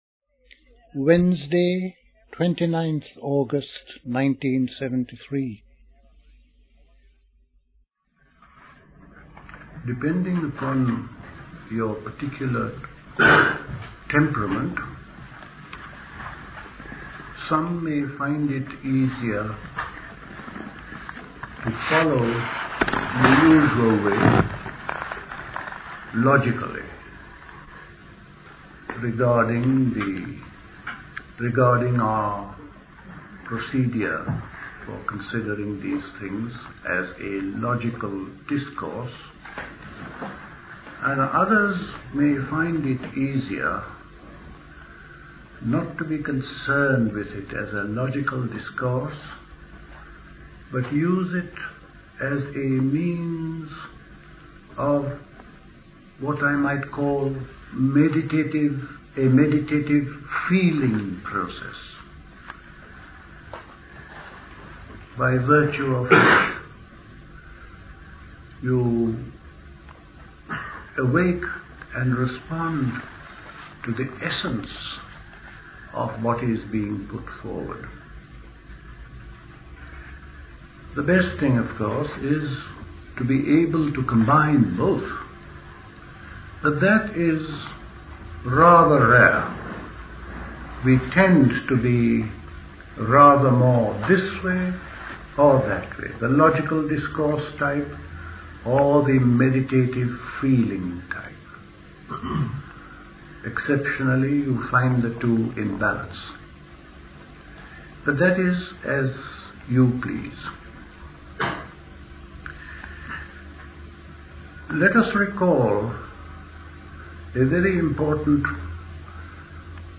Recorded at the 1973 Buddhist Summer School.